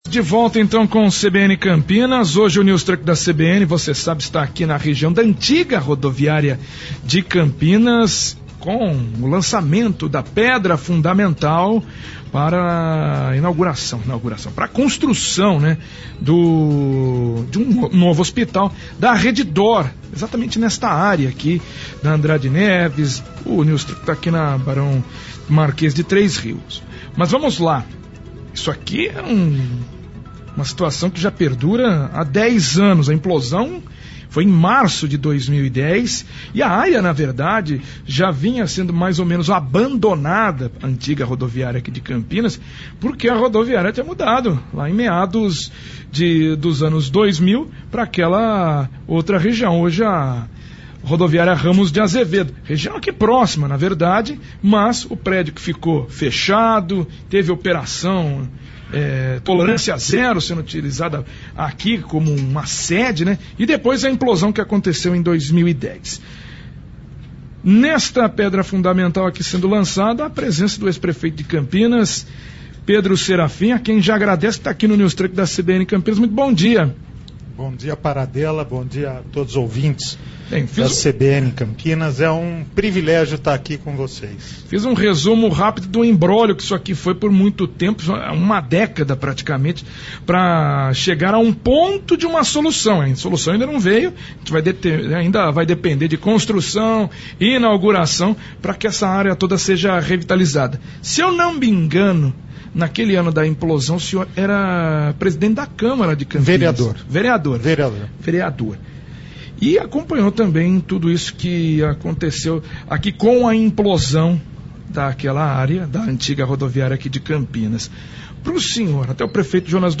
Ex-prefeito de Campinas, Pedro Serafim (Republicanos) participa do lançamento da pedra fundamental da rede d’or a convite e concede entrevista no News Truck da CBN Campinas